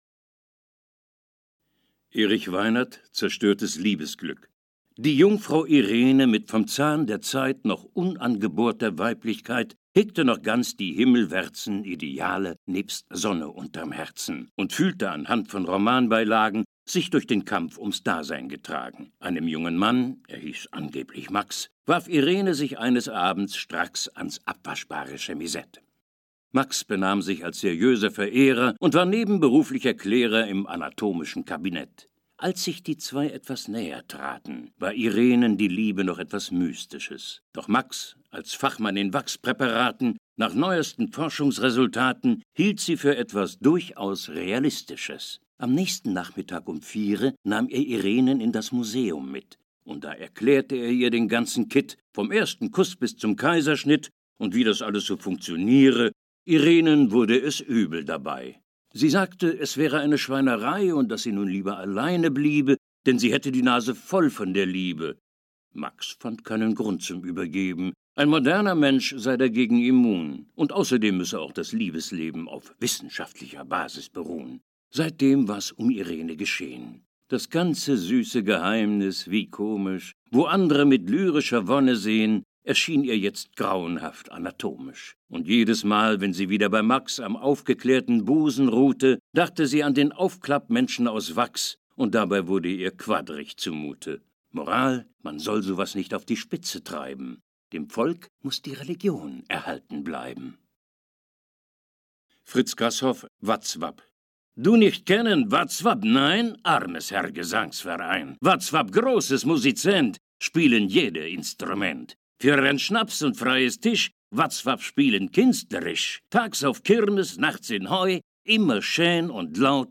Sorry, Dein Browser unterstüzt kein HTML5 Prosa Doku